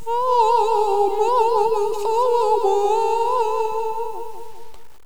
minarett4.wav